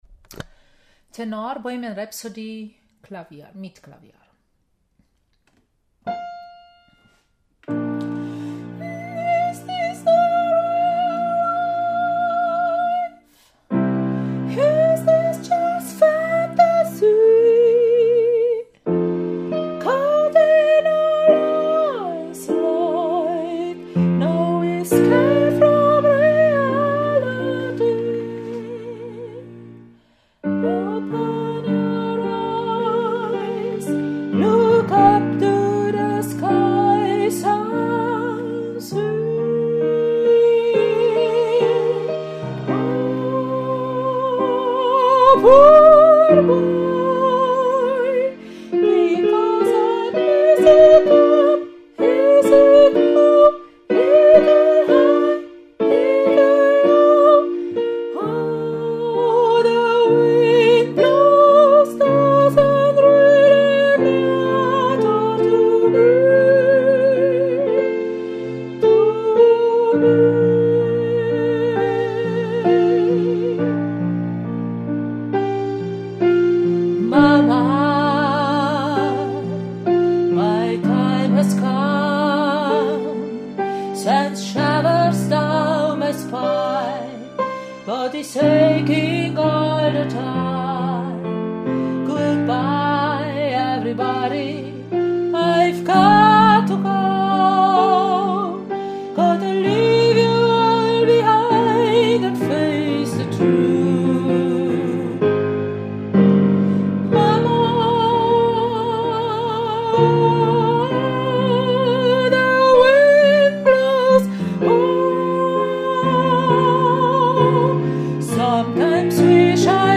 Bohemian Rhapsody – Tenor mir Klavier